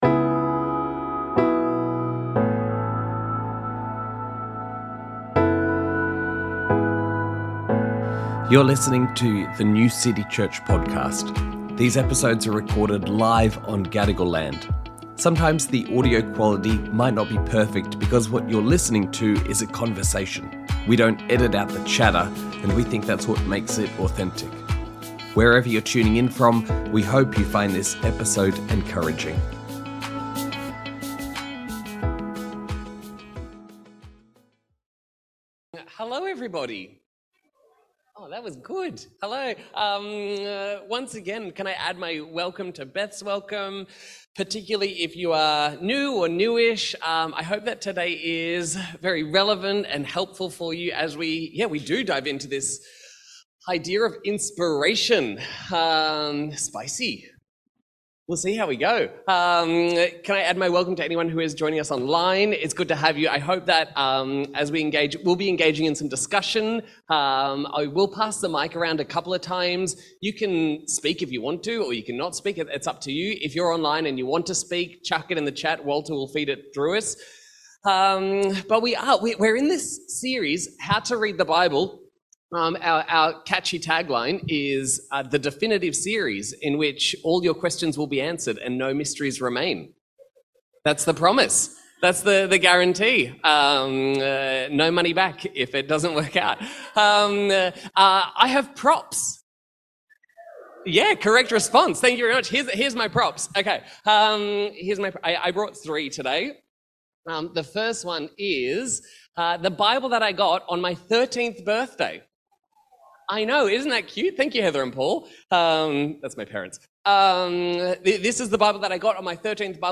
Sermons | New City Church